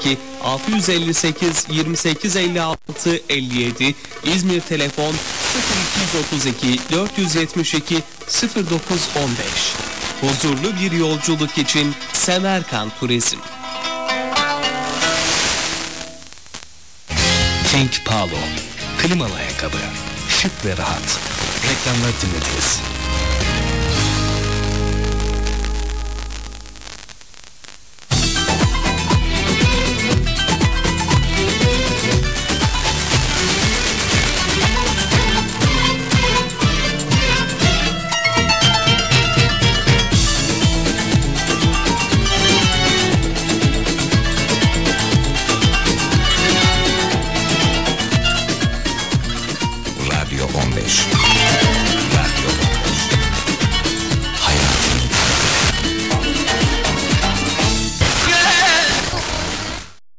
The first good Sporadic E-opening for me was on the 28.5.2008.
There were strong signals from Romania and Bulgaria here in southwest Finland.